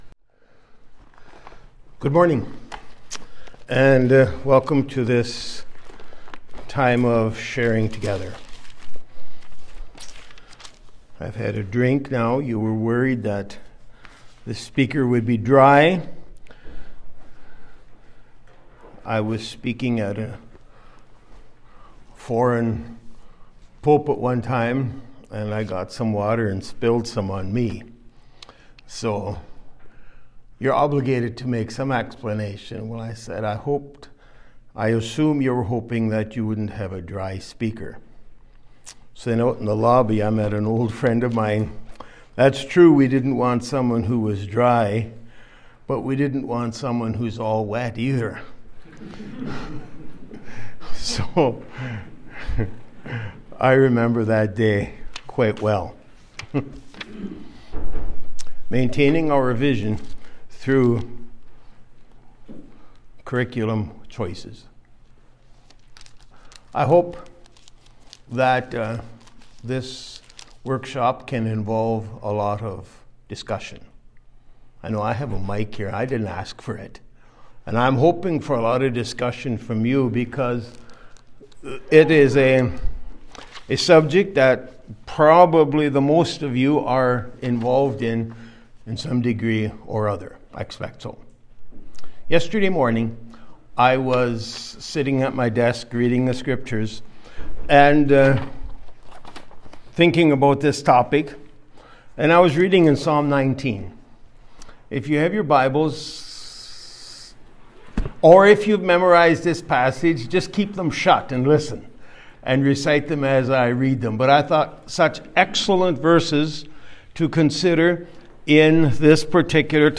Christian School Administration Institute 2024 (CSAI)